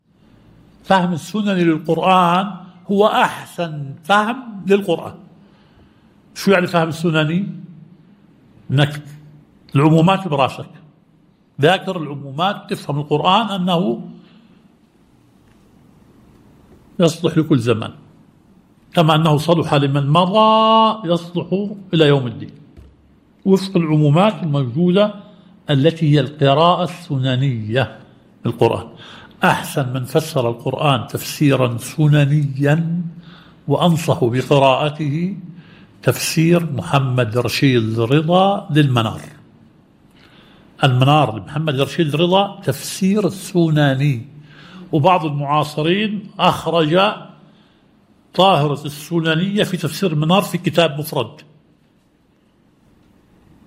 الدرس التاسع – شرح مبحث العام والخاص في أصول الفقه